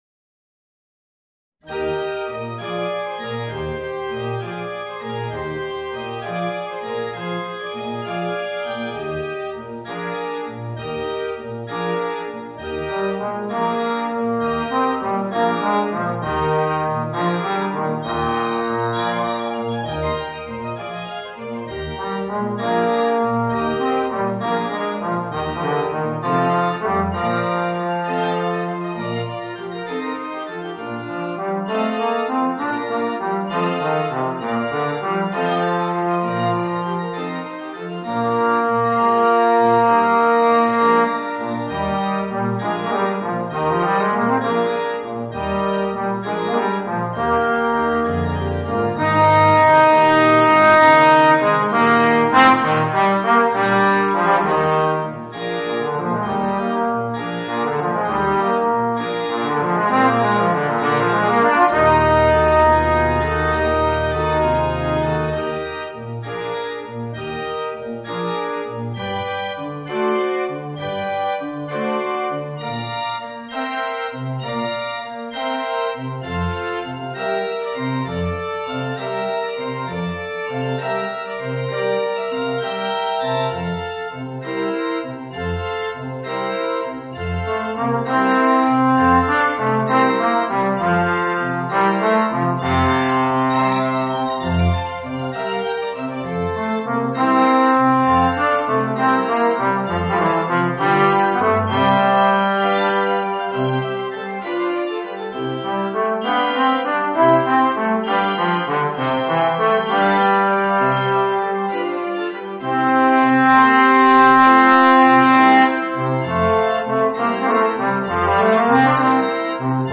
Voicing: Trombone and Organ